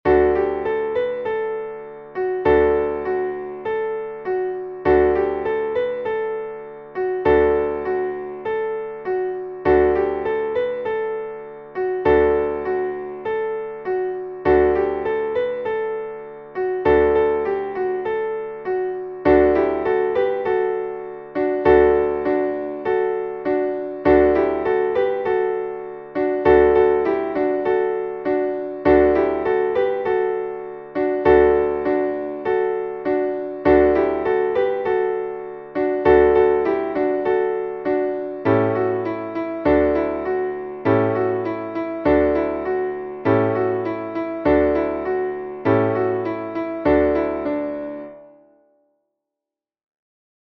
Πάσχακάλαντα
δίφωνη χορωδία και συγχορδίες, Dmaj